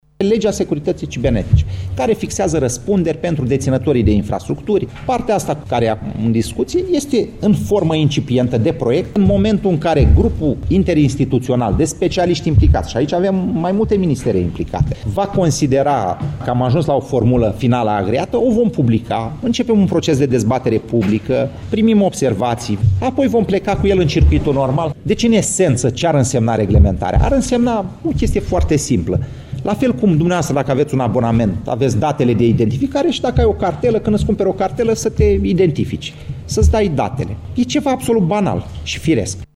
Așa a anunţat, la Cluj, ministrul Comunicaţiilor, Marius Bostan.